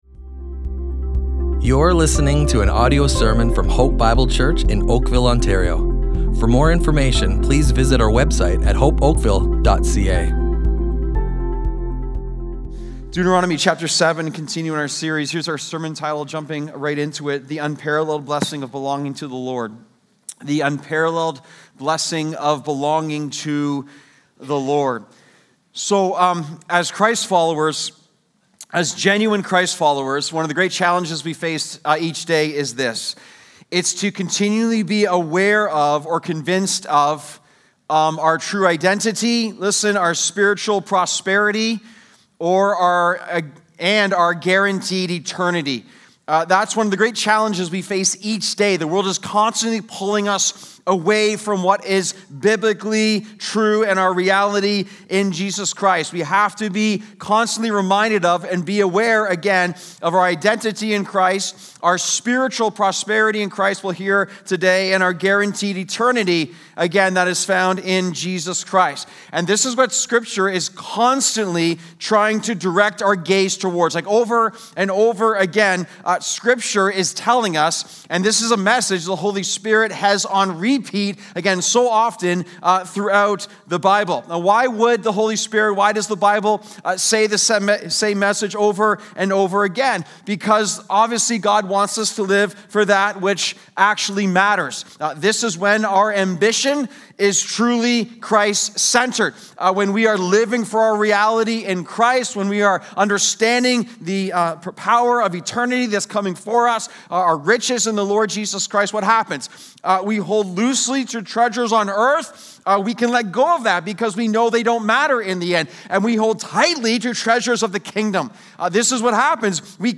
Hope Bible Church Oakville Audio Sermons Listen and Love // The Unparalleled Blessing of Belonging to the Lord!